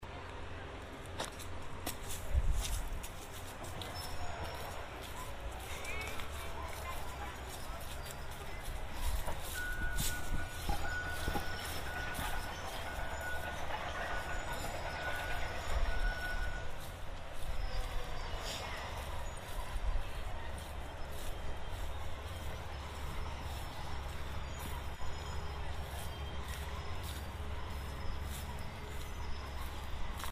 jumps down, end of the improv, construction sounds, walk back, end of recording for the day